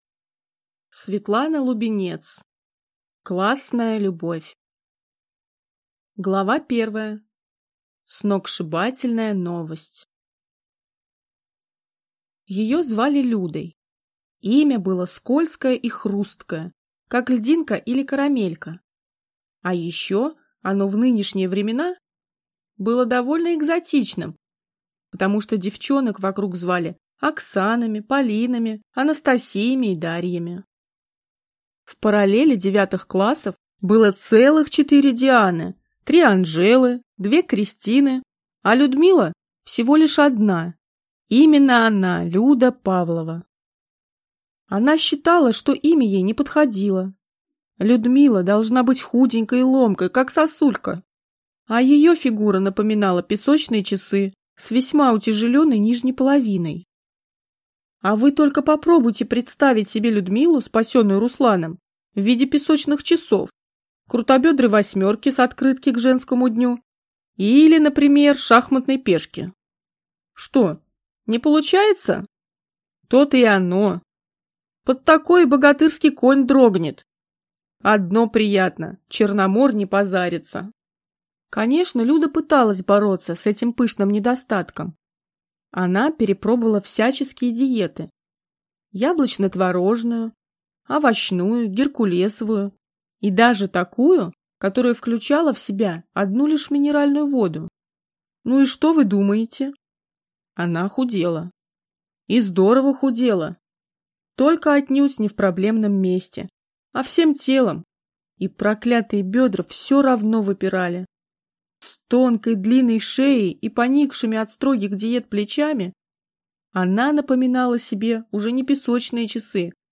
Аудиокнига Классная любовь | Библиотека аудиокниг